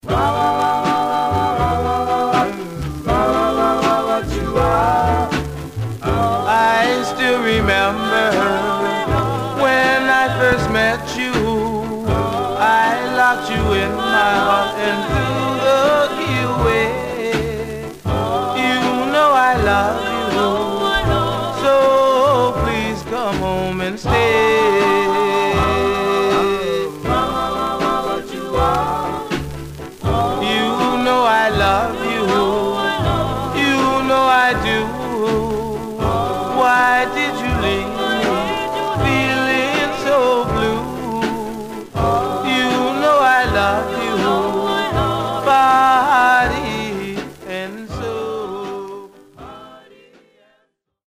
Some surface noise/wear
Mono
Male Black Group